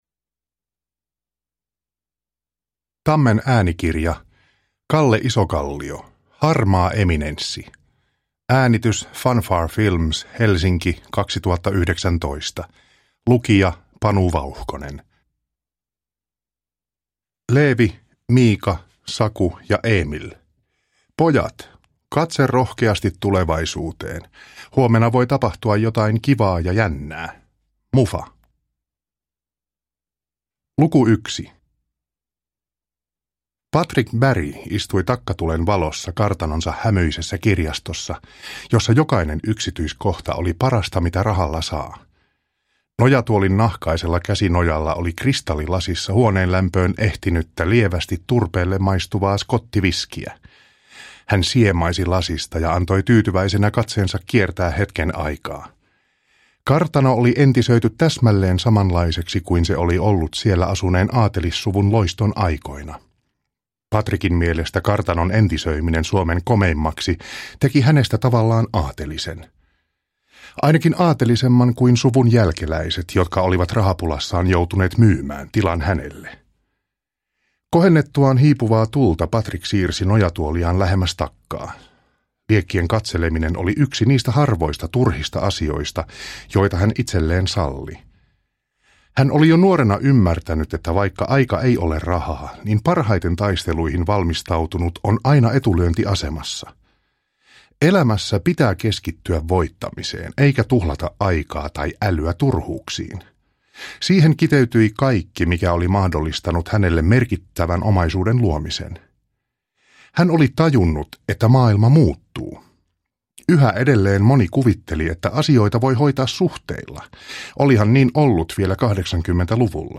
Harmaa eminenssi – Ljudbok